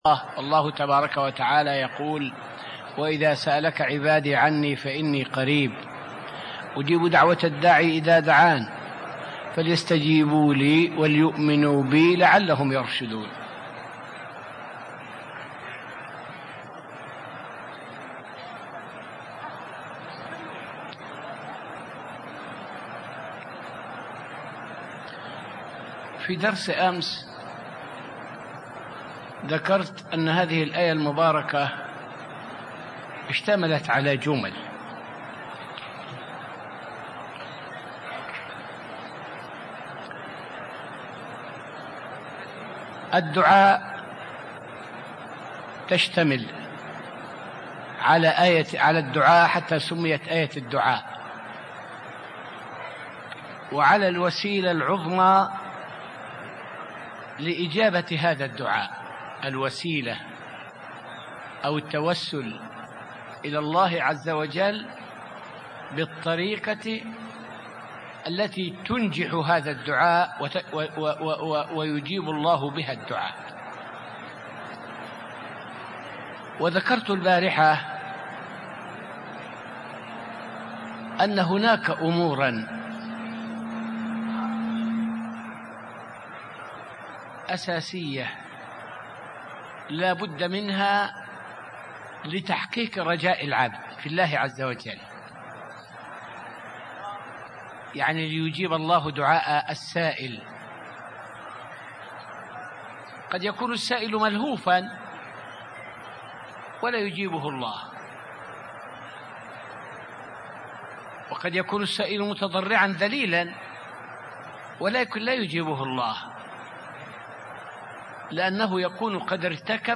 فائدة من الدرس الواحد والعشرون من دروس تفسير سورة البقرة والتي ألقيت في المسجد النبوي الشريف حول موانع استجابة الدعاء.